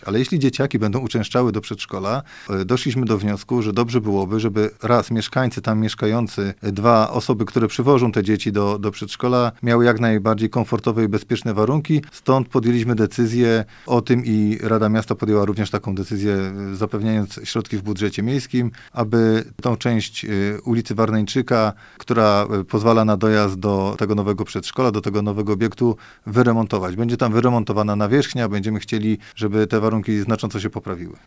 Jak mówi wiceprezydent Mielca Paweł Pazdan wraz z oddaniem tej placówki do użytku planowana jest także modernizacja drogi dojazdowej do przedszkola.